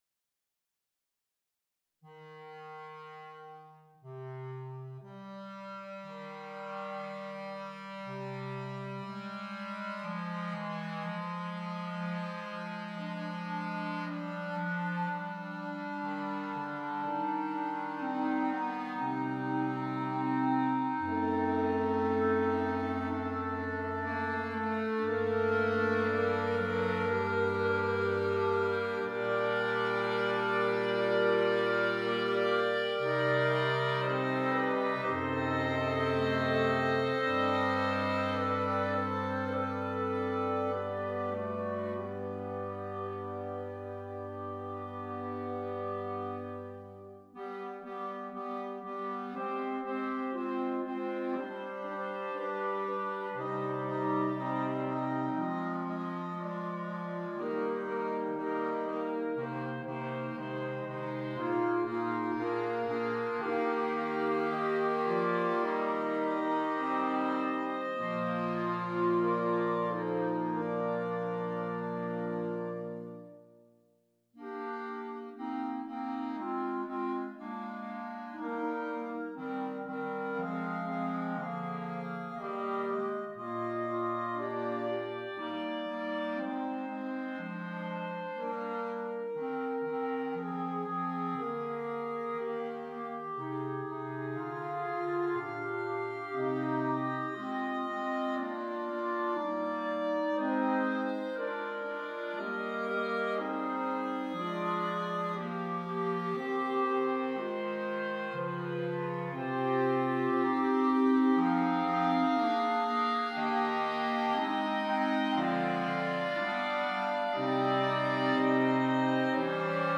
6 Clarinets, 2 Bass Clarinets